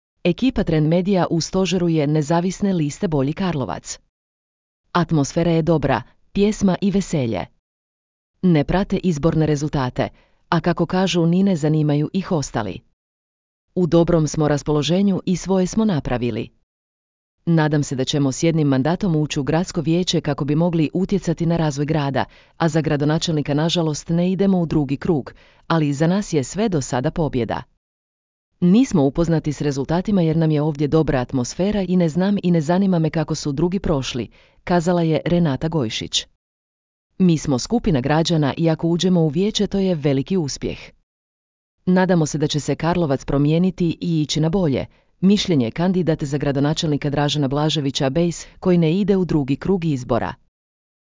Ekipa Trend medija u stožeru je Nezavisne liste Bolji Karlovac. Atmosfera je dobra, pjesma i veselje.